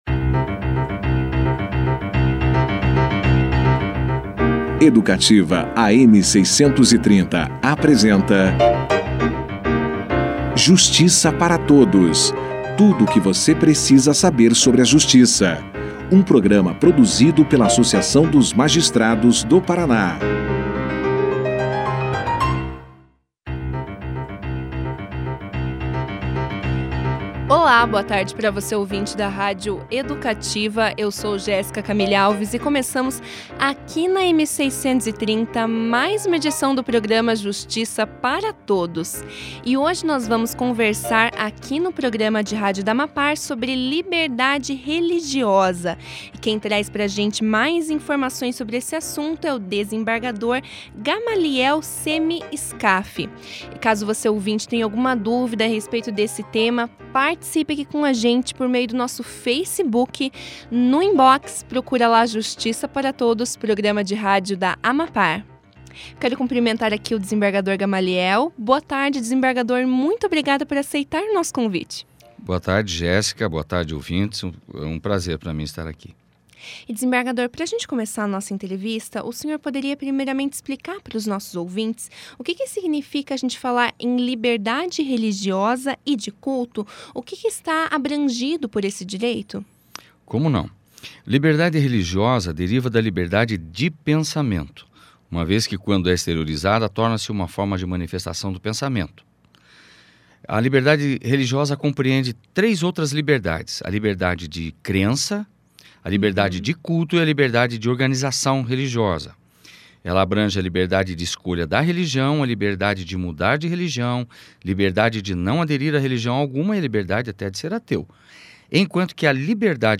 O programa Justiça para Todos recebeu nesta terça-feira (28), nos estúdios da rádio Educativa, AM 630, o desembargador Gamaliel Seme Scaff. O magistrado explicou aos ouvintes do programa um pouco mais sobre o tema do dia: liberdade religiosa.